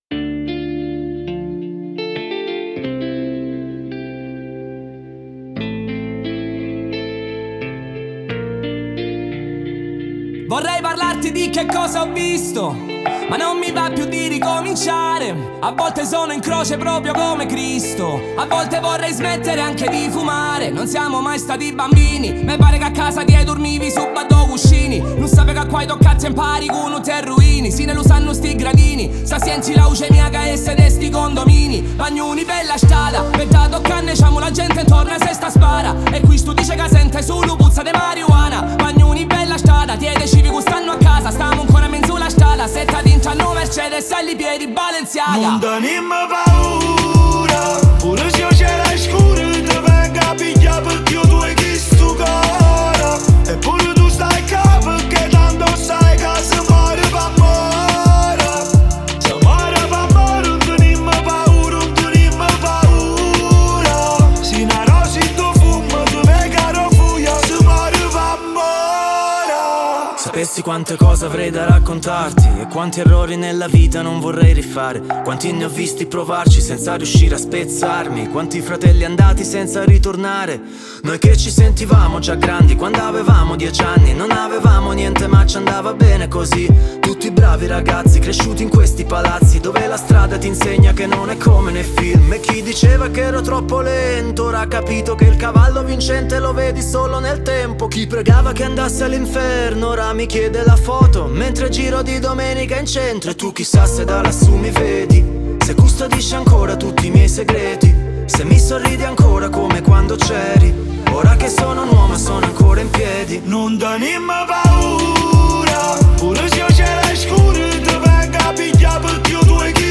это зажигательная композиция в жанре регги и поп